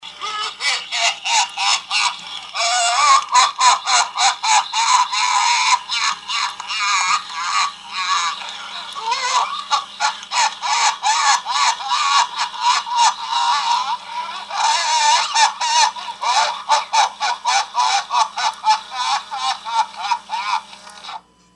Liberals laughing at Elon Musk!
Laughing.mp3